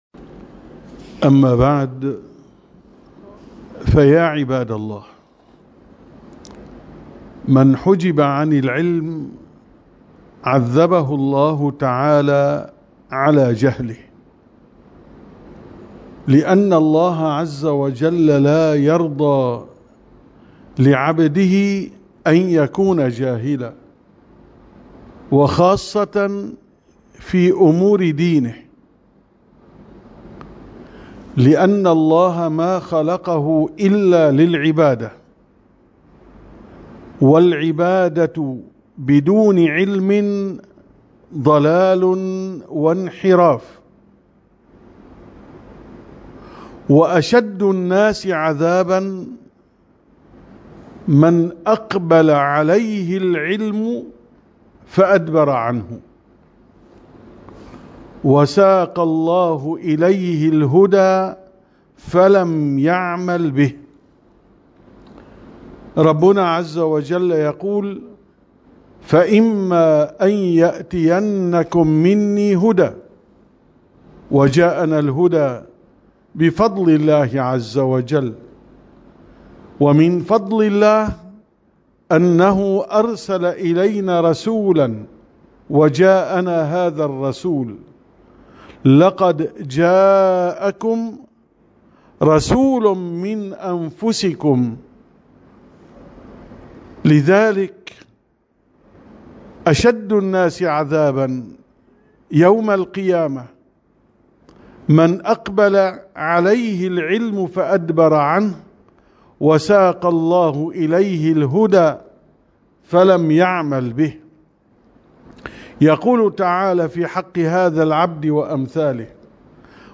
952ـ خطبة الجمعة: كمال شخصية الداعي